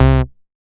MoogAgressPulseB.WAV